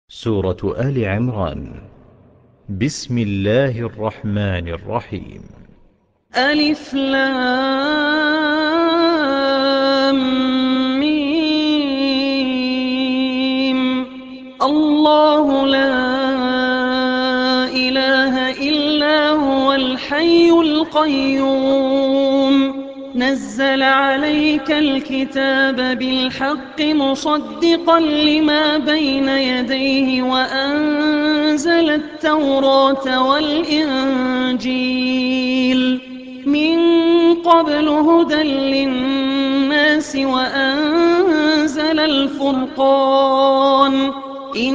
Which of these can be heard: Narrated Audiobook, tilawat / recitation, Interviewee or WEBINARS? tilawat / recitation